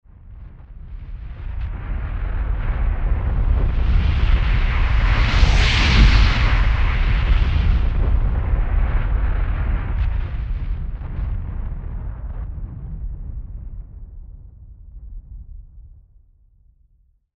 Gemafreie Sounds: Atmosphären
mf_SE-3455-cast_fireball.mp3